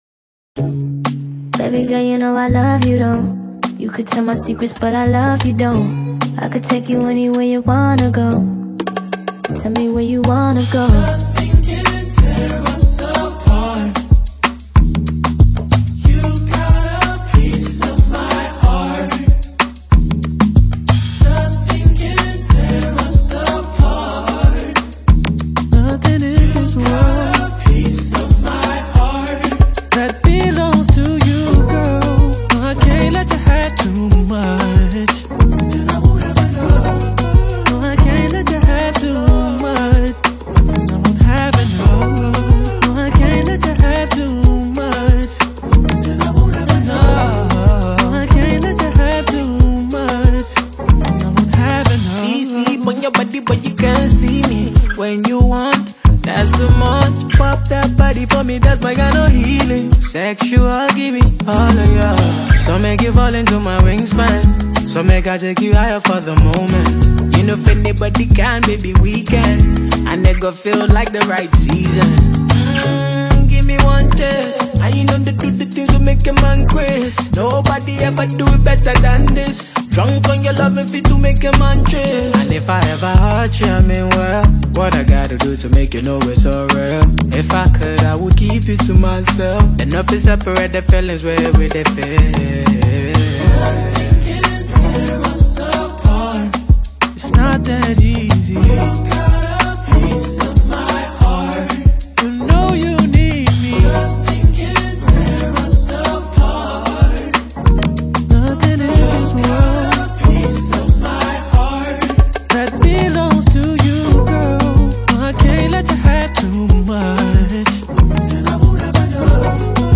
a Nigerian Afrobeat lyricist who has won numerous awards
hauntingly beautiful collaboration
soul- stirring single
Experience a melodious rhythm